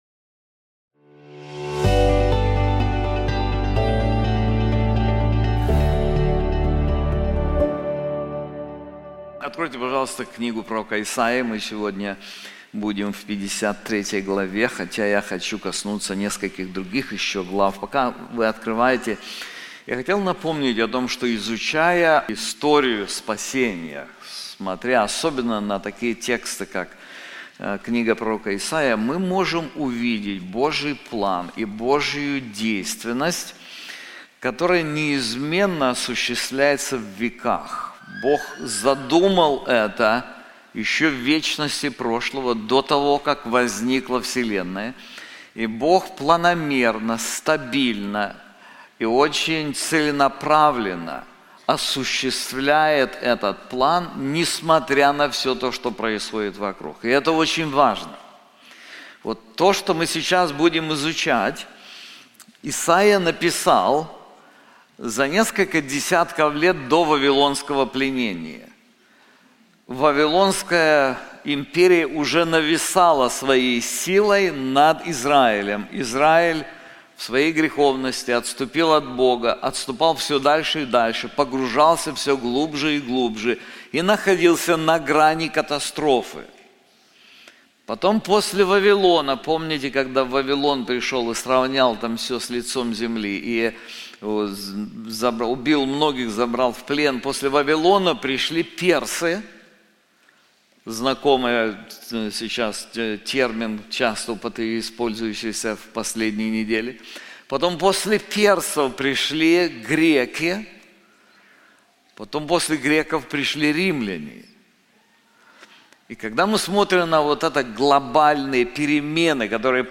This sermon is also available in English:Substitutionary Sacrifice • Isaiah 53:4-6